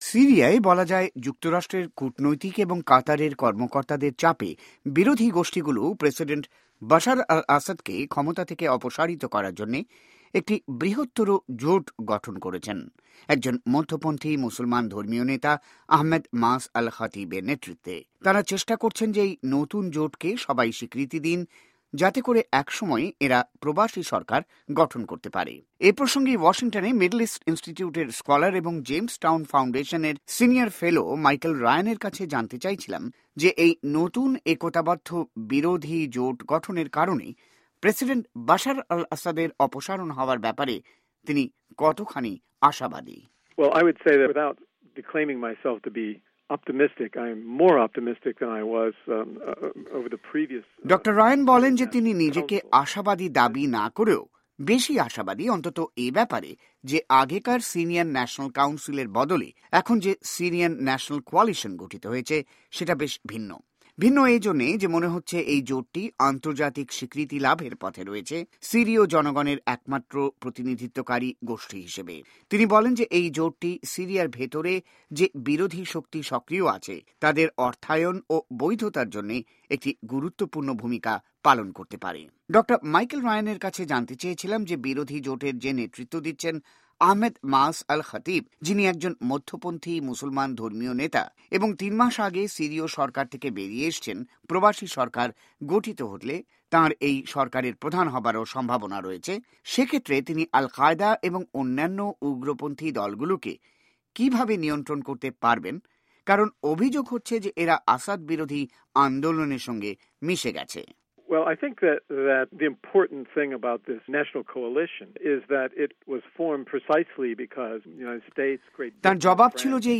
সাক্ষাতকার